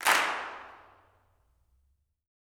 CLAPS 09.wav